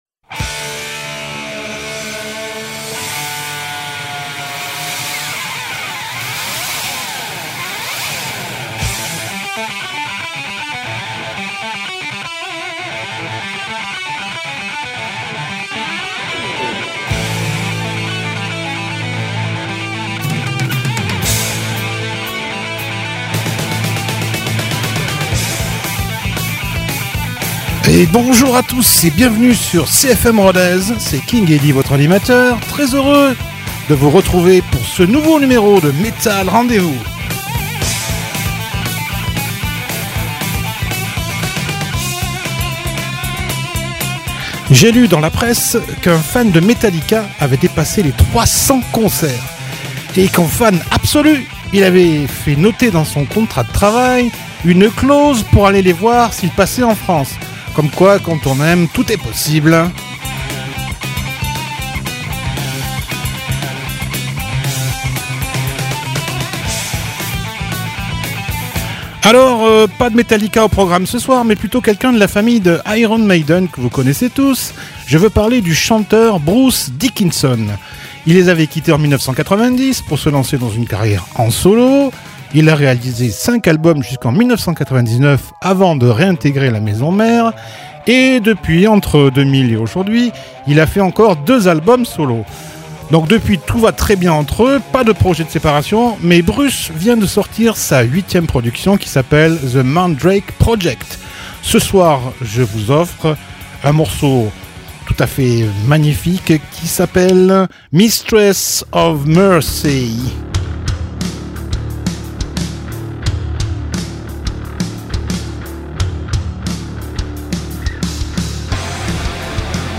Une plongée dans les abysses du métal